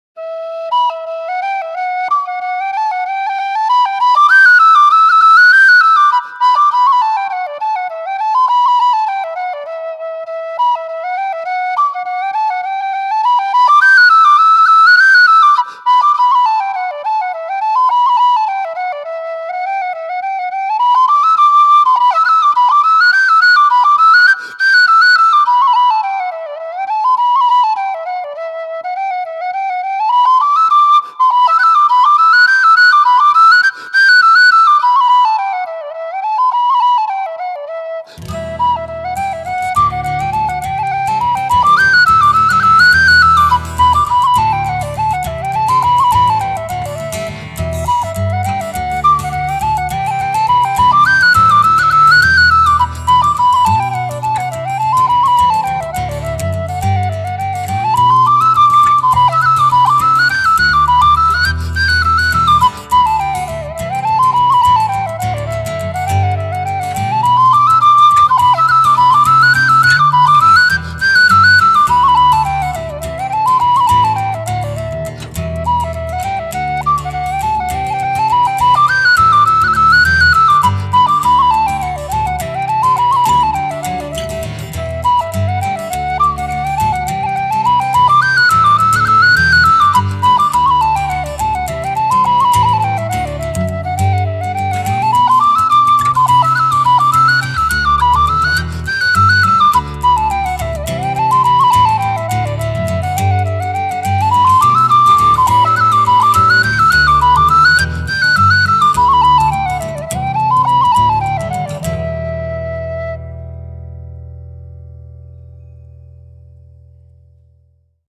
Guitar
much more jazzy than the first two
marlin-spike-mix.mp3